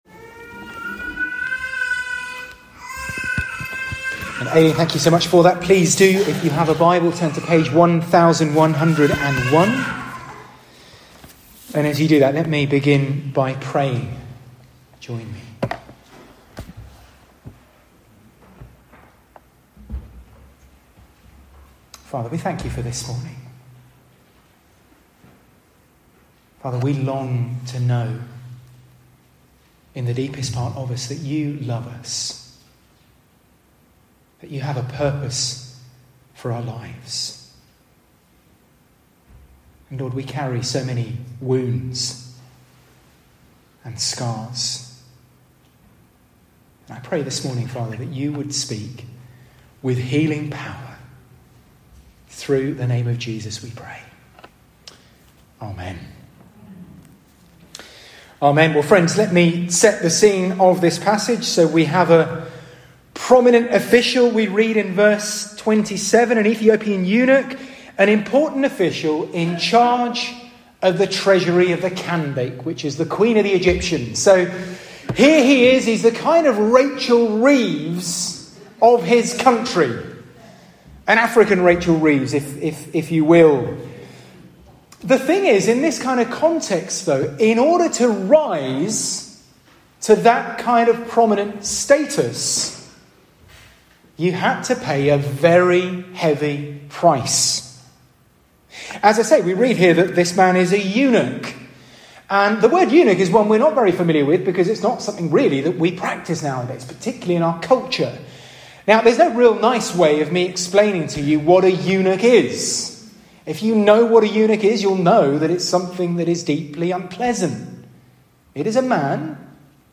SERMON-26TH-JANUARY.mp3